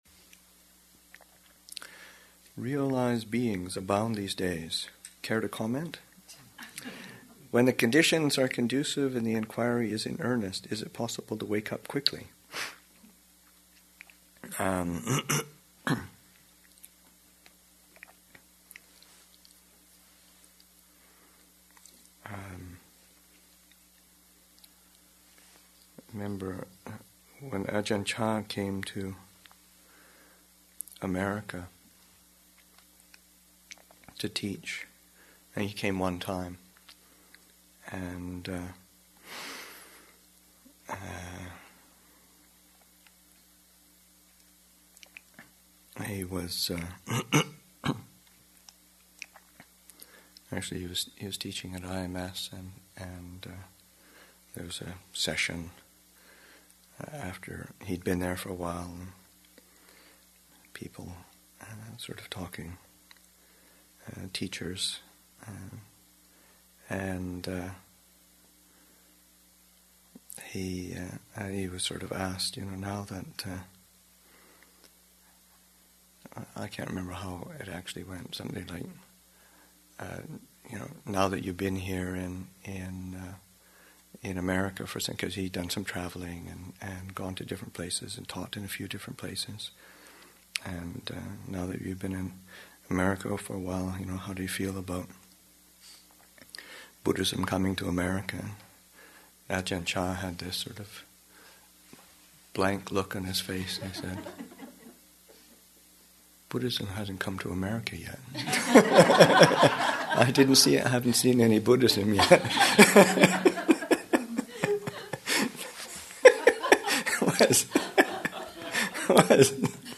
Metta Retreat, Session 4 – Sep. 12, 2008